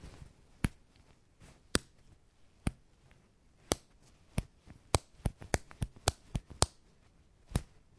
描述：在这里，我试图收集我在家里发现的所有扣件。其中大部分在夹克上，一个手提包上有啷个球，还有一些雪地裤。
Tag: 点击 服装和-配件 扣紧固件